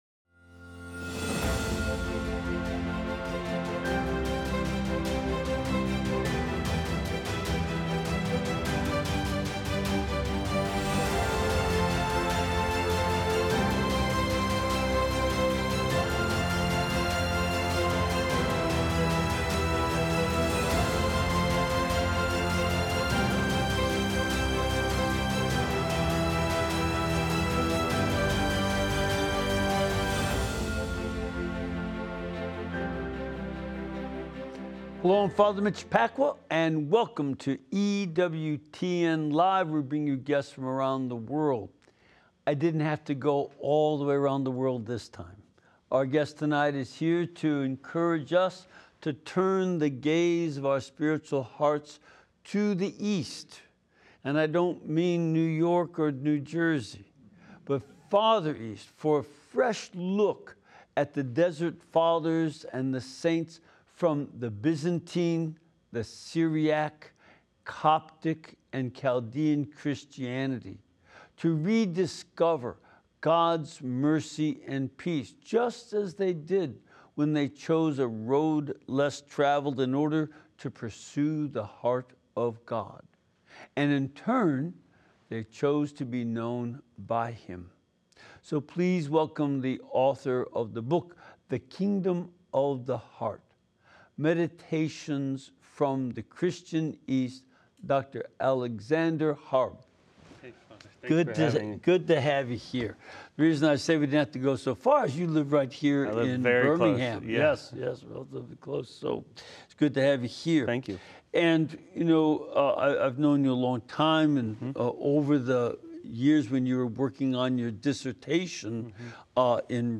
interviews various guests seeking to teach and prepare us for evangelism. Learn more about your faith and reawaken your desire to bring others to the Church.